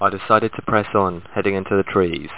home *** CD-ROM | disk | FTP | other *** search / Horror Sensation / HORROR.iso / sounds / iff / presson.snd ( .mp3 ) < prev next > Amiga 8-bit Sampled Voice | 1992-09-02 | 22KB | 1 channel | 9,016 sample rate | 2 seconds